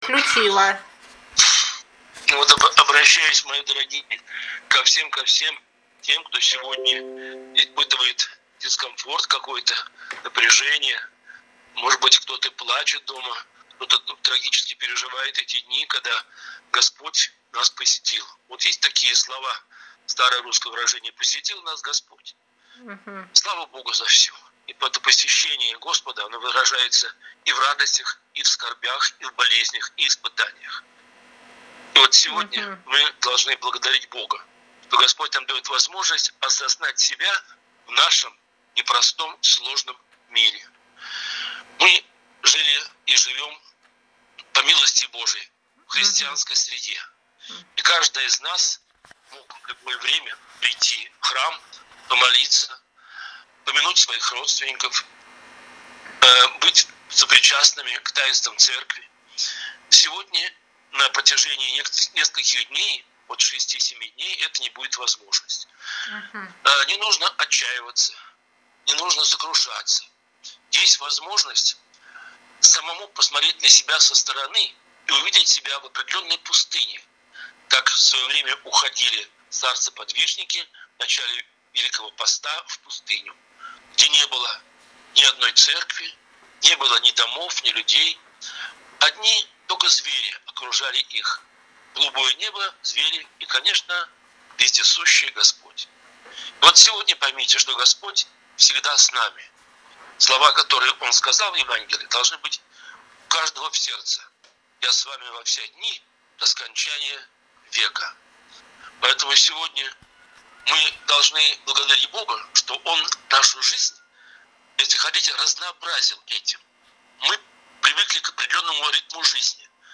В разгар эпидемии, когда многие люди не имеют возможности ходить в храм, епископы и священники записали проповеди для тех верующих, которые проводят дни Великого поста вдали от храма.